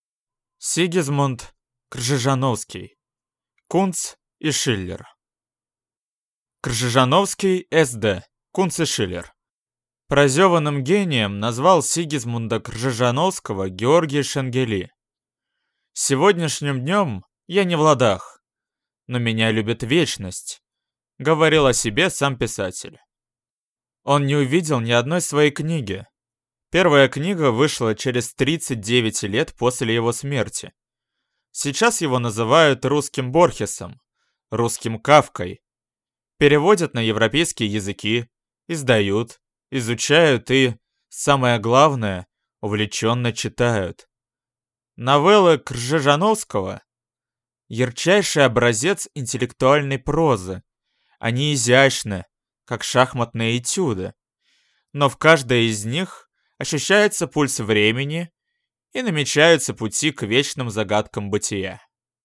Аудиокнига Кунц и Шиллер | Библиотека аудиокниг